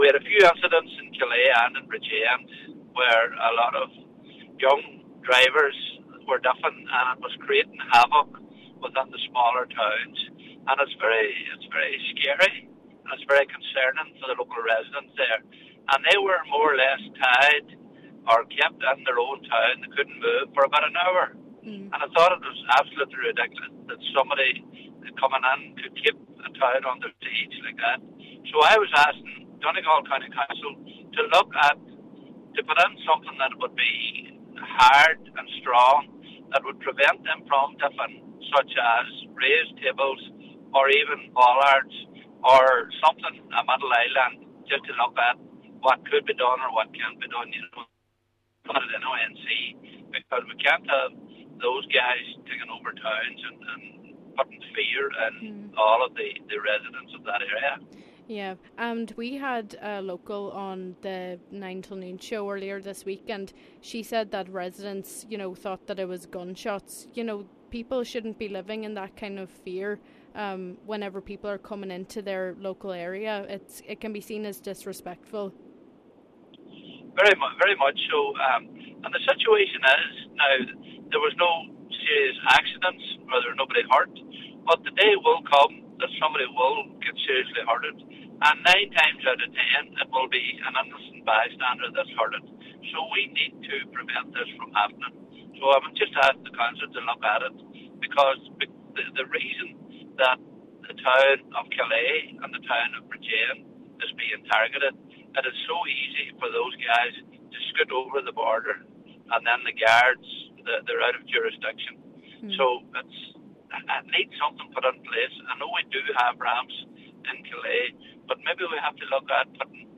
Councillor Paul Canning says there are also serious safety concerns surrounding such events.